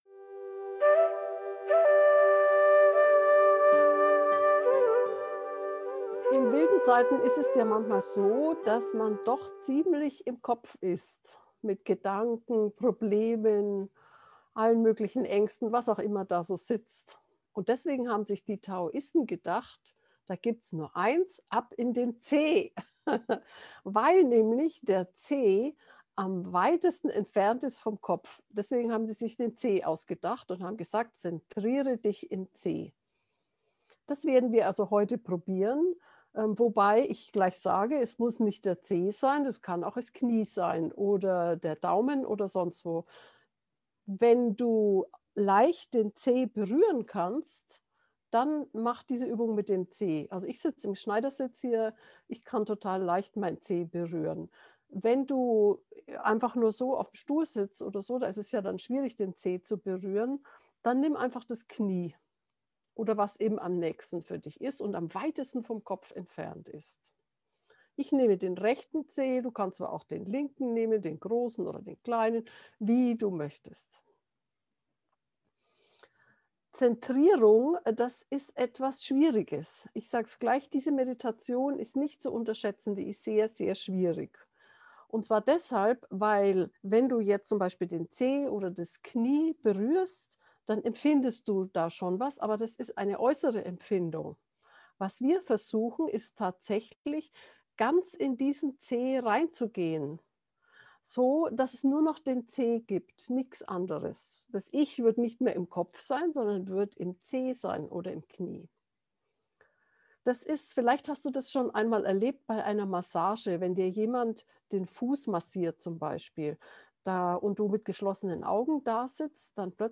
Geführte Meditationen Folge 120: Aus dem Kopf in den Zeh Play Episode Pause Episode Mute/Unmute Episode Rewind 10 Seconds 1x Fast Forward 10 seconds 00:00 / 17:31 Subscribe Share RSS Feed Share Link Embed